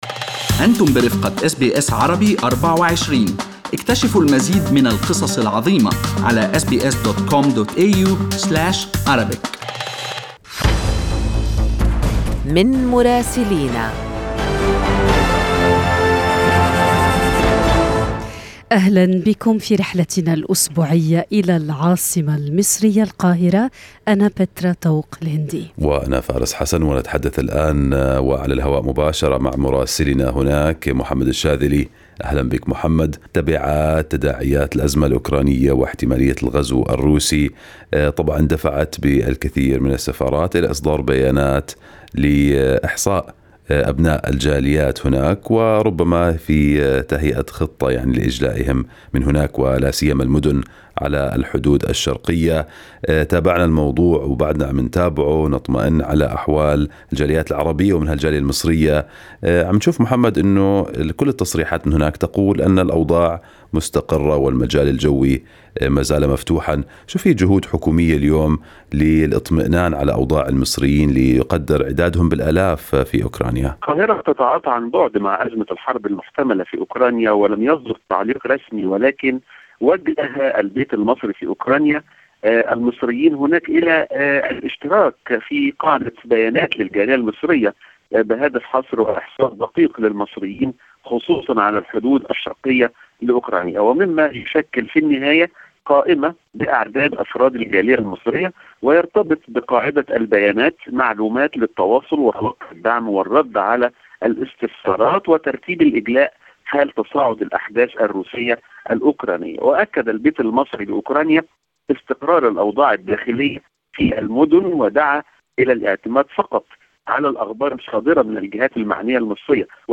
من مراسلينا: أخبار مصر في أسبوع 16/2/2022